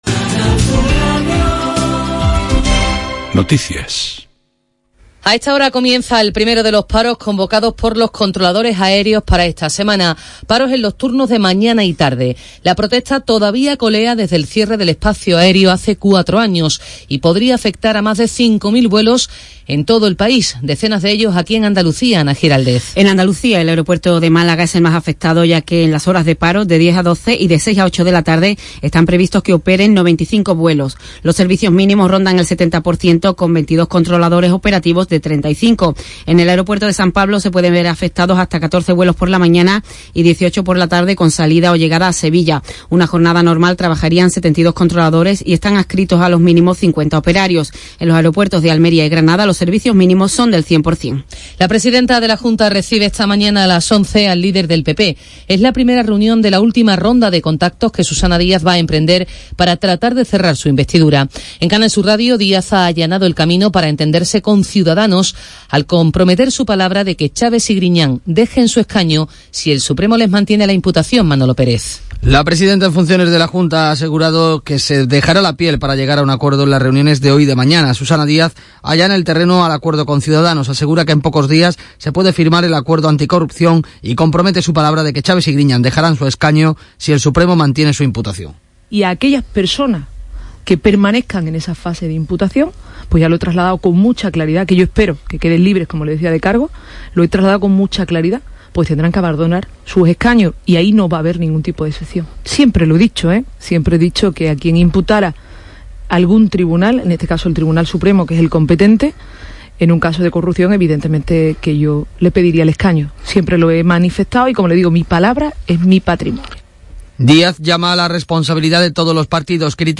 atendiendo además a llamadas en directo o vía las redes sociales.
Mi intervención empieza en la 2 hora, minuto 6.